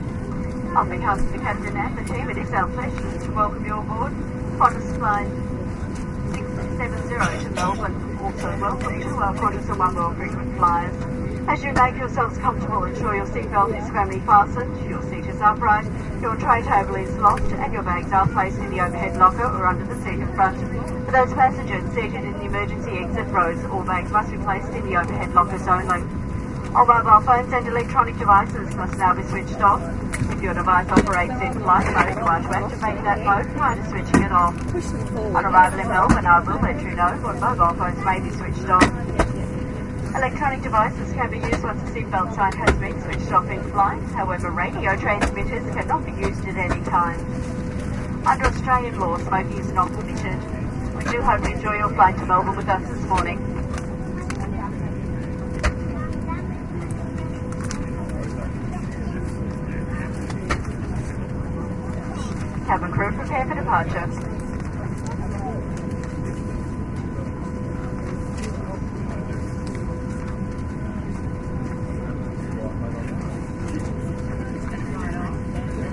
运输 " 飞行安全讲座
描述：喷气式飞机上的飞行安全讲座
标签： 飞机 飞机 公告 现场记录 喷射 噪声 平面
声道立体声